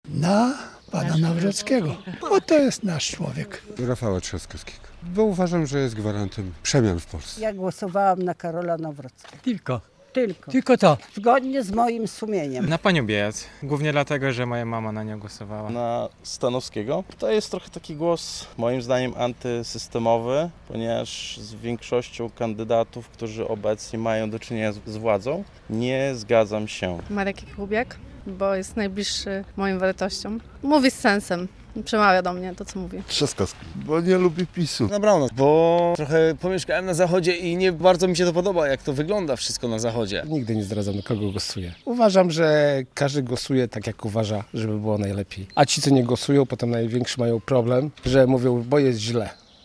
Na kogo głosowali mieszkańcy Białegostoku? - relacja